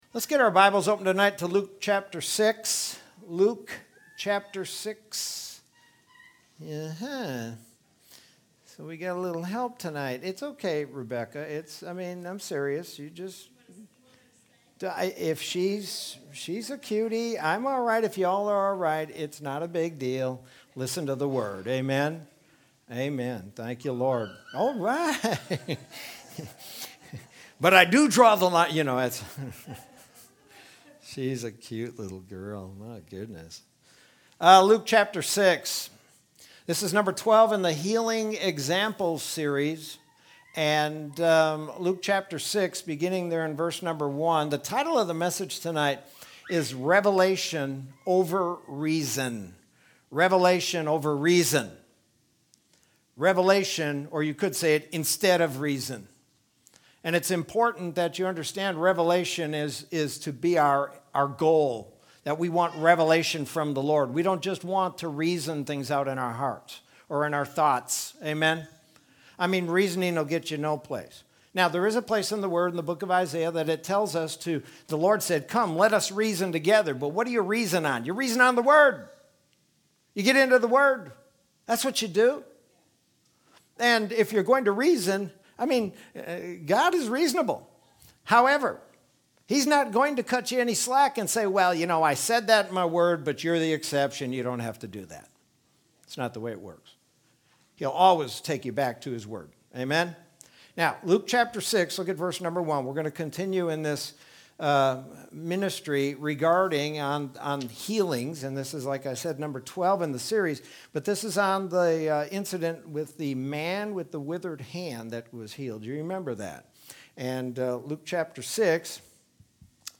Sermon from Wednesday, April 14th, 2021.